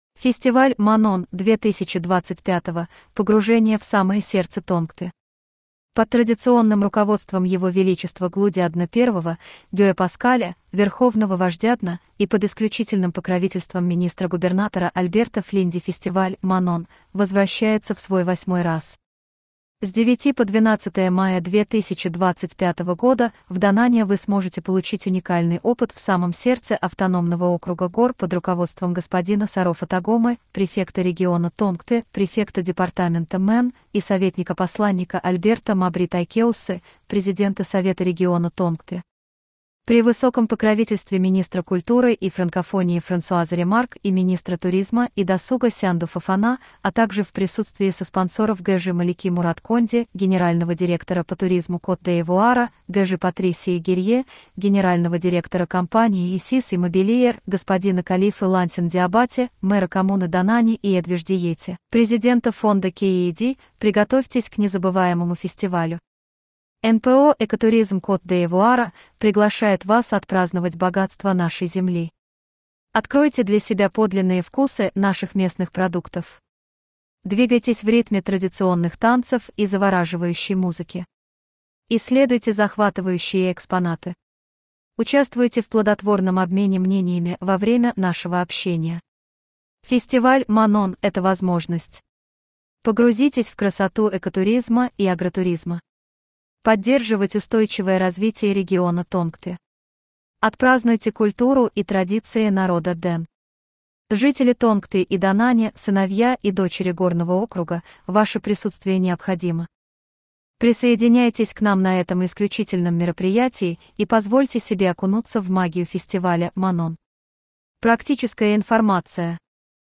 Аудиоверсия видеоклипа:
spot-russe.ogg